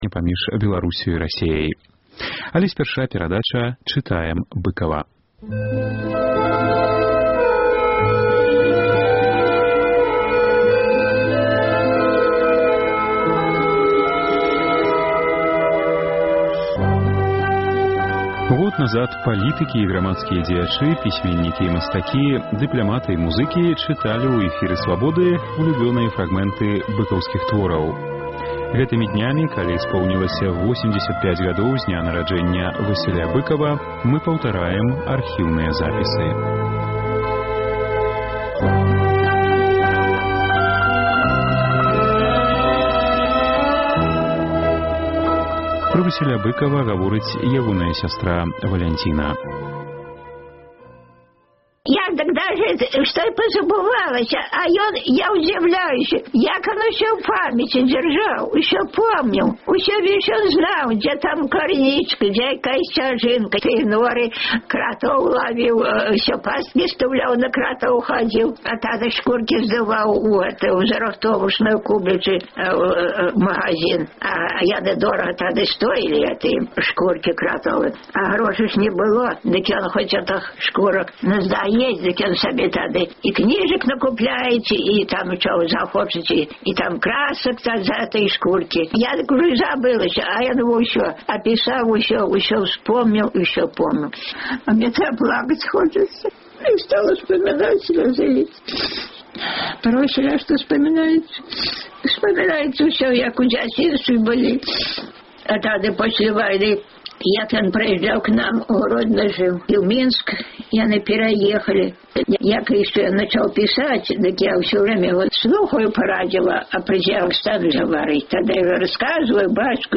Год таму палітыкі і грамадзкія дзеячы, пісьменьнікі і мастакі, дыпляматы і музыкі чыталі ў эфіры Свабоды ўлюблёныя фрагмэнты быкаўскіх твораў. Гэтымі днямі, калі споўнілася 85-гадоў з дня нараджэньня Васіля Быкава, мы паўтараем архіўныя запісы.